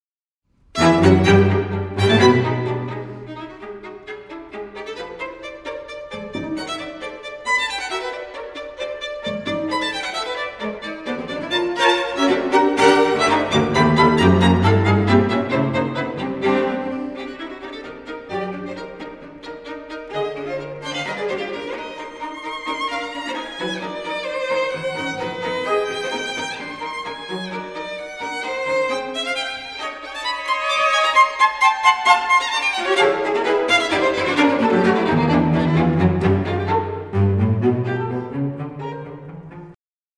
Swiss string quartet.